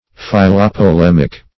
Philopolemic \Phil`o*po*lem"ic\